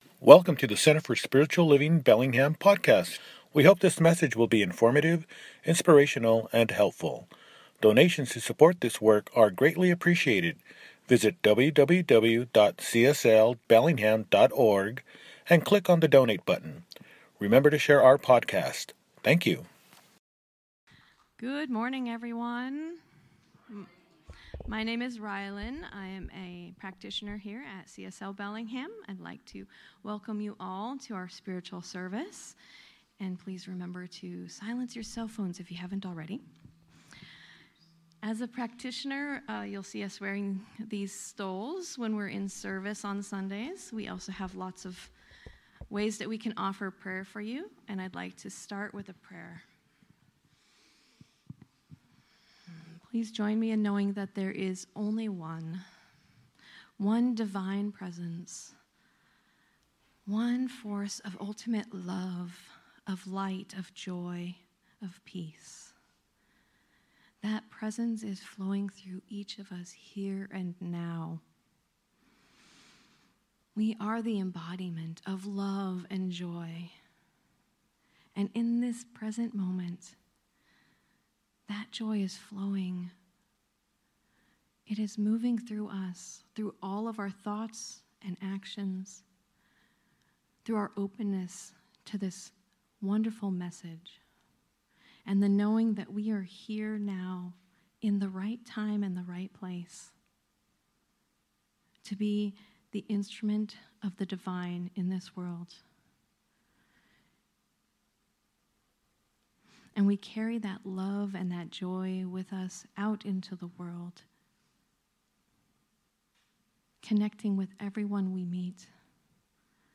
It’s Never Too Late and It’s Never Too Soon– Celebration Service | Center for Spiritual Living Bellingham